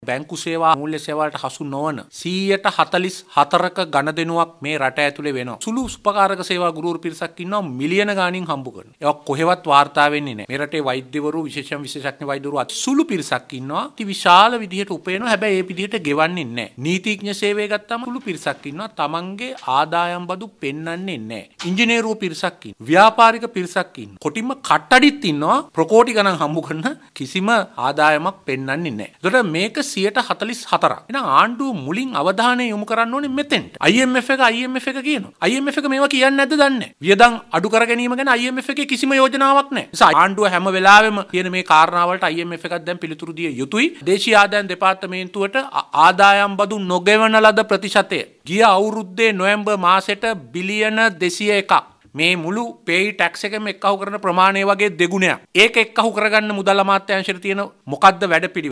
එමෙන්ම ආදායම් සඟවාගෙන විශාල වශයෙන් ඉපැයීම් සිදුකරන ක්ෂේත්‍ර කිහිපයක්ම තිබෙන බවද මාධ්‍ය හමුවකට එක්වෙමින් ඒ මහතා කියා සිටියා.